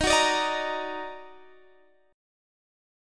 Listen to D#mM7 strummed